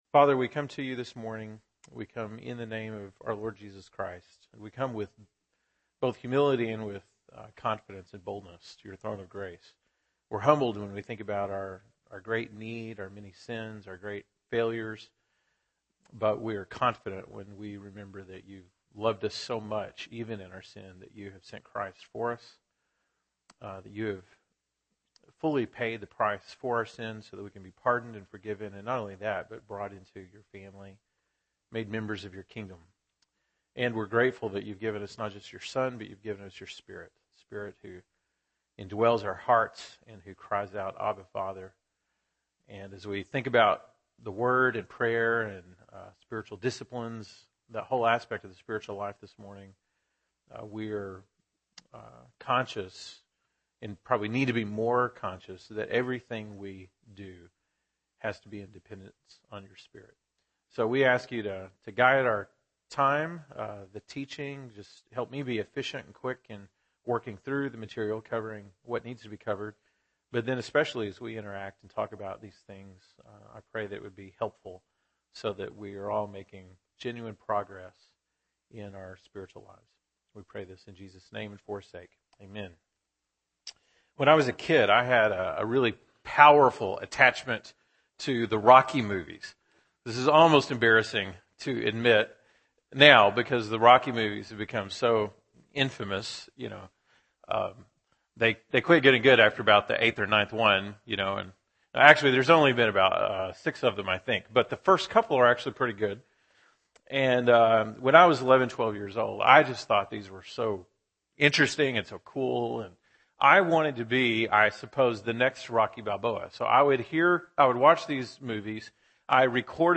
November 23, 2014 (Sunday School)